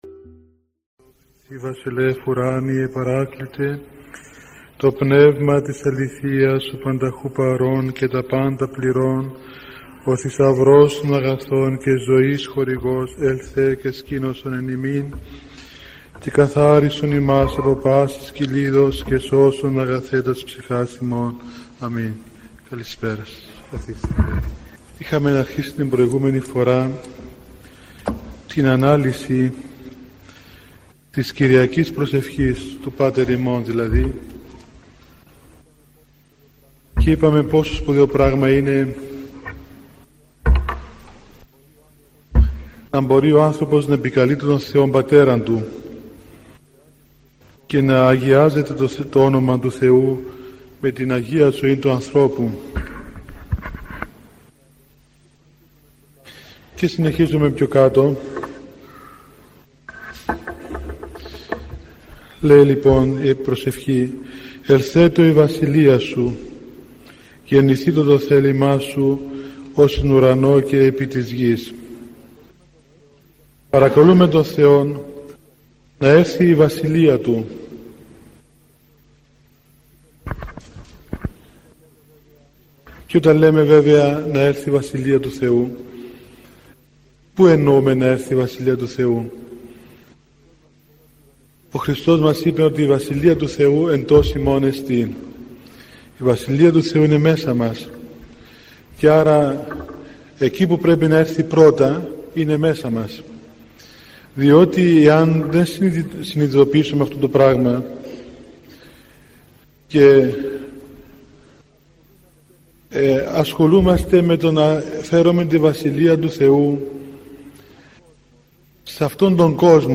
Από την Ιερά Μητρόπολη Λεμεσού, αναρτούμε την πρόσφατη και ψυχωφέλιμη ομιλία του Πανιερωτάτου κ. Αθανασίου, με τίτλο «Το θέλημα του Θεού».